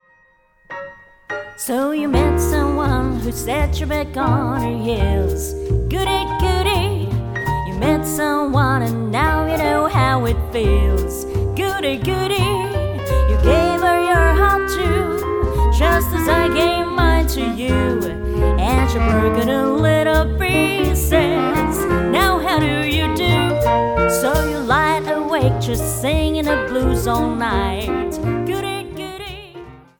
Vibraphone
Piano
Bass
Drums